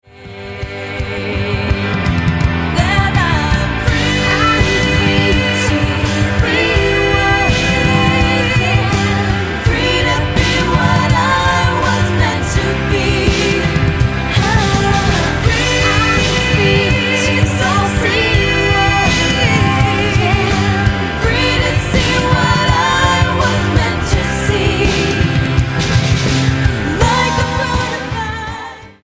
American girl trio
Style: Pop